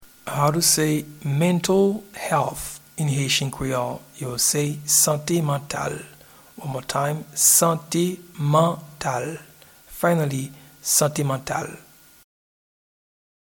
Pronunciation and Transcript:
Mental-Health-in-Haitian-Creole-Sante-Mantal.mp3